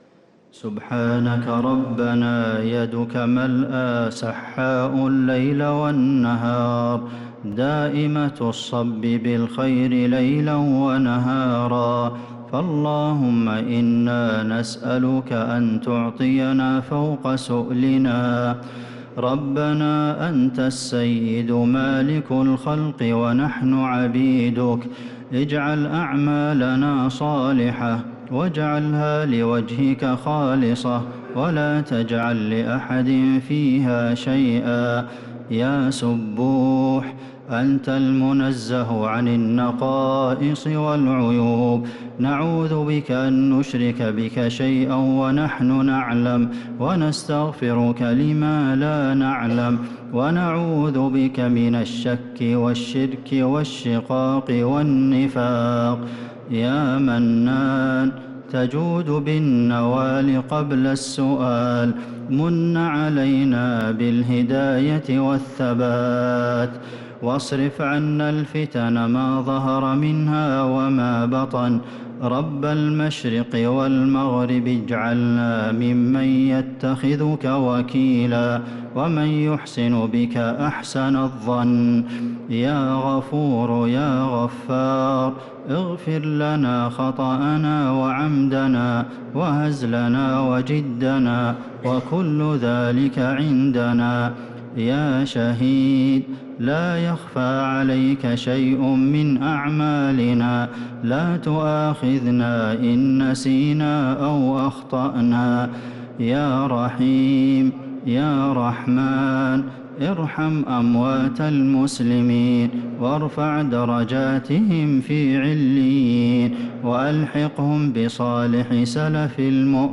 دعاء القنوت ليلة 12 رمضان 1446هـ | Dua for the night of 12 Ramadan 1446H > تراويح الحرم النبوي عام 1446 🕌 > التراويح - تلاوات الحرمين